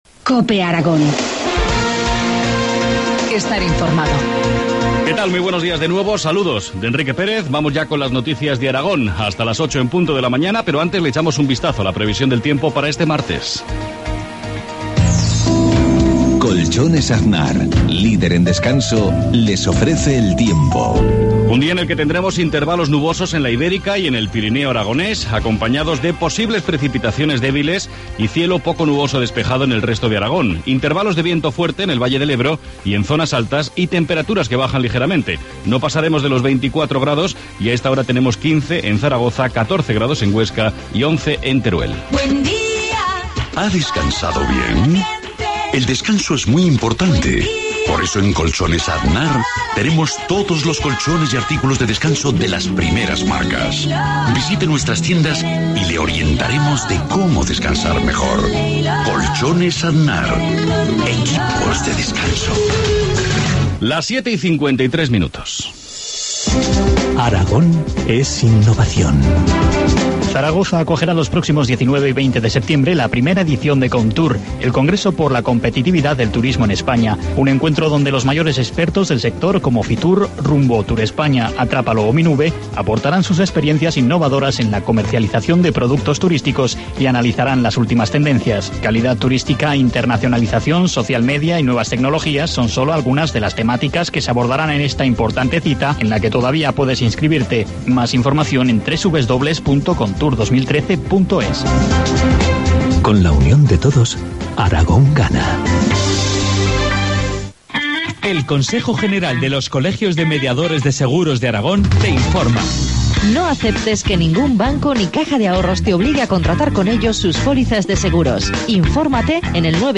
Informativo matinal, martes 17 septiembre, 2013, 7,25 horas